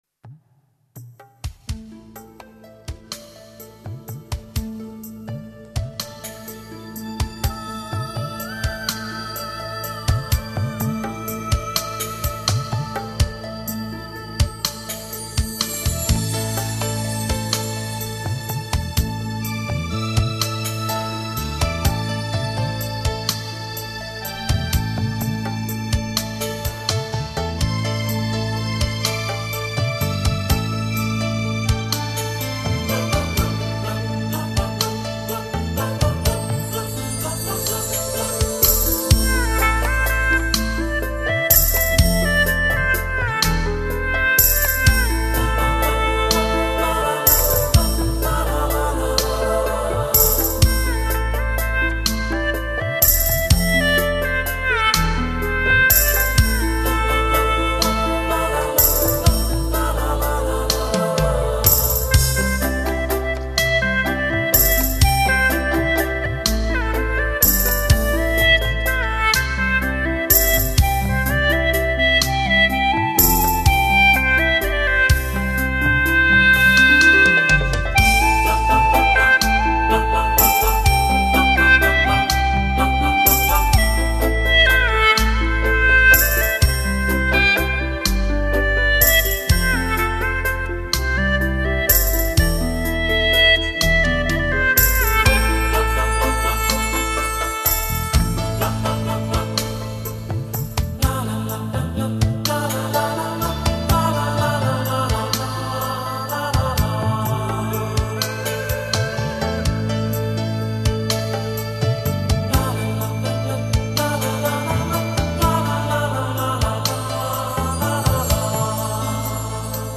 葫芦丝为云南少数民族乐器。其音色轻柔细腻，圆润质朴，极富表现力，深受云南人民喜爱。
所演绎的曲目也极尽轻柔幻美感受，使人聆听之下仿若处身影蝶纷飞，孔雀祥云天际，柔美妙韵非它等可比拟。
精彩的现代配器，原汁原味的民族风格，让你用全新的方式来欣赏音乐。